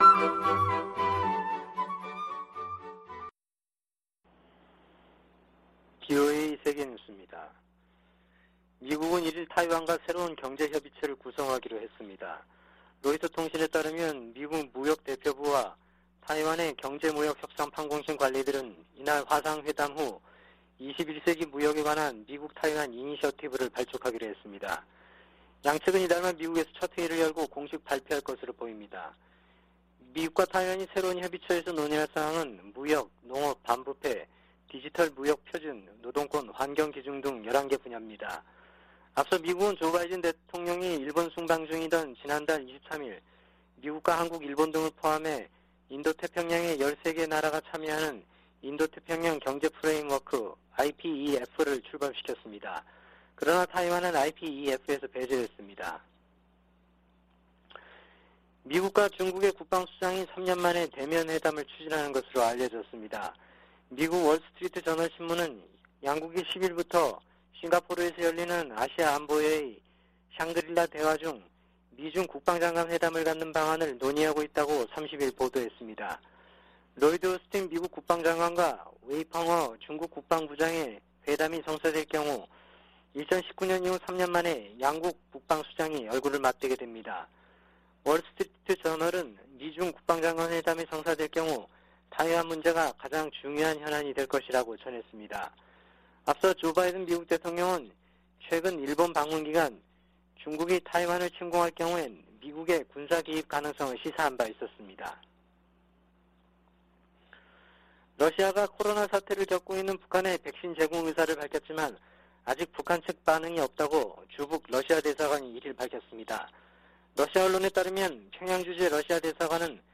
VOA 한국어 아침 뉴스 프로그램 '워싱턴 뉴스 광장' 2022년 6월 2일 방송입니다. 미국은 북한이 핵 실험을 실시한다면 유엔 안보리에서 추가 제재를 추진할 것이라고 유엔주재 미국 대사가 밝혔습니다. IPEF가 미국과 인도 태평양 지역 국가들 간 경제 관계를 더욱 강화할 것이라고 미 상무장관이 말했습니다. 미 중앙정보국(CIA)은 최근 갱신한 ‘국가별 현황보고서’에서 북한의 올해 ICBM 시험을 주목하고, 극심한 식량부족이 우려된다고 지적했습니다.